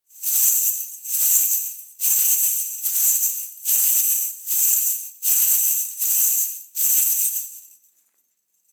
Danza árabe, bailarina da un golpe de cadera con un pañuelo de monedas, movimiento continuo 01
Sonidos: Acciones humanas